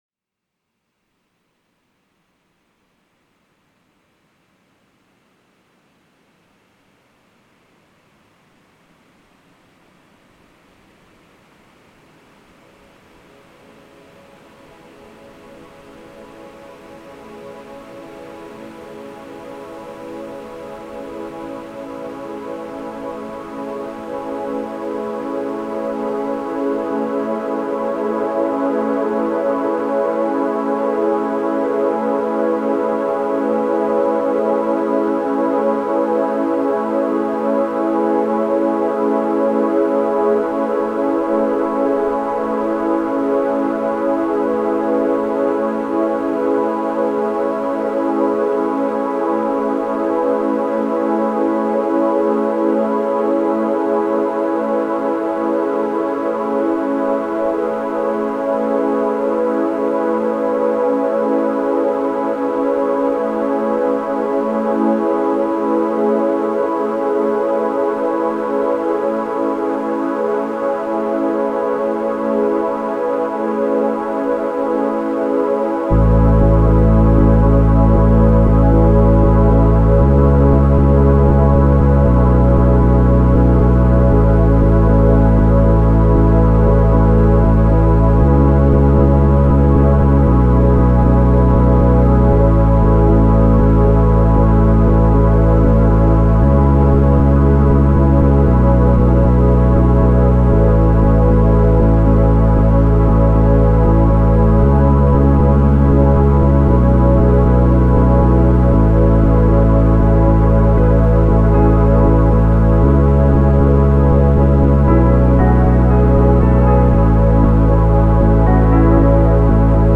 Genre: Ambient/Deep Techno/Dub Techno/Electro.